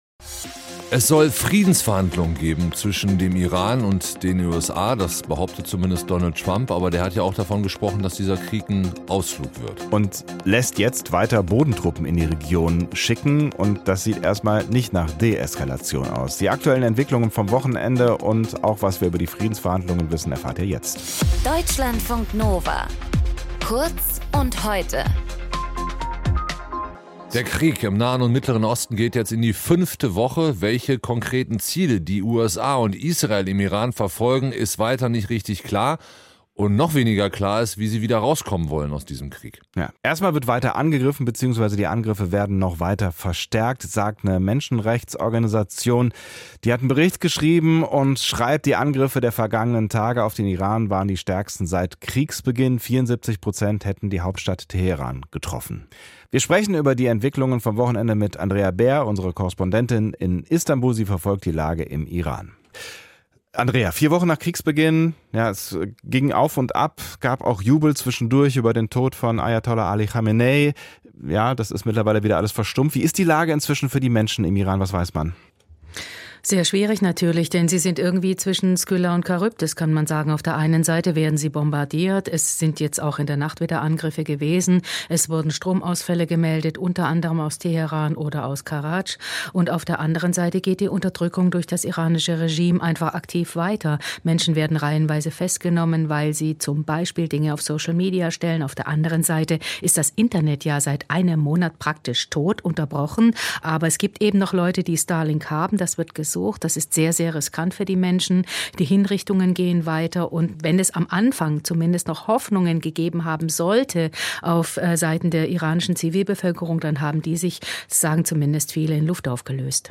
Moderation: